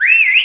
whistle01.mp3